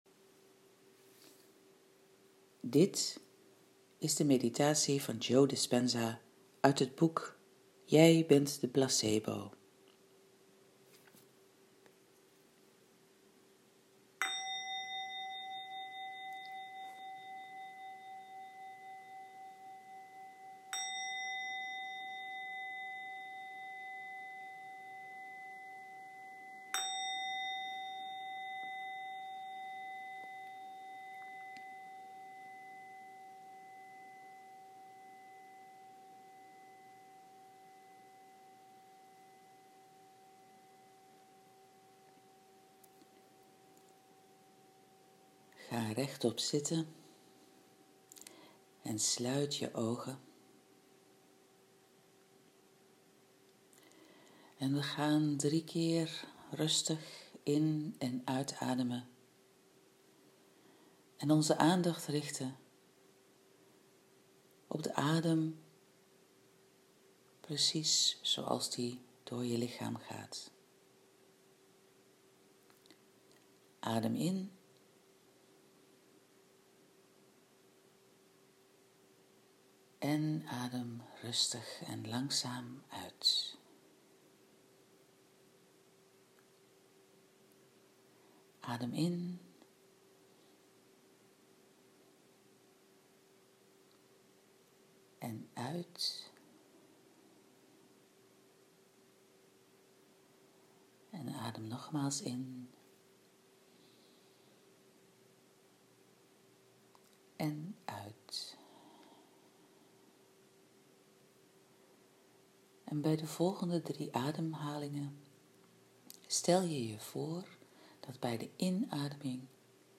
Geleide meditatie | Zen Centrum Eindhoven